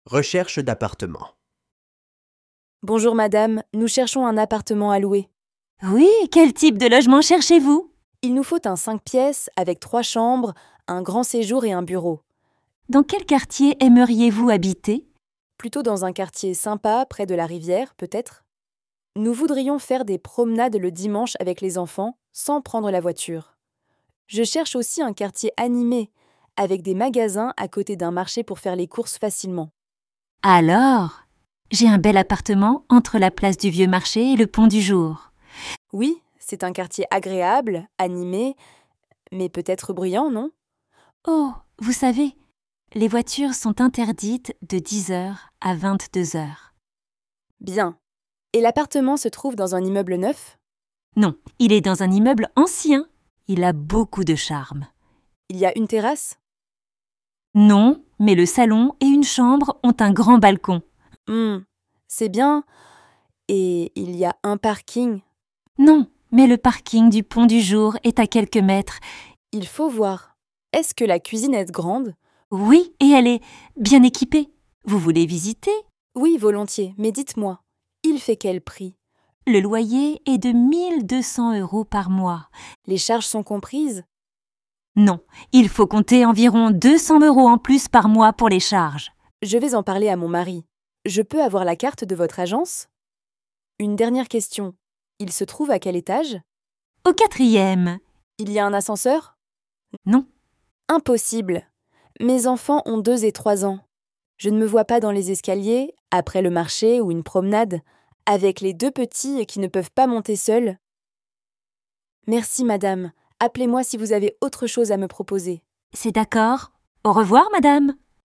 Dialogue – Recherche d’Appartement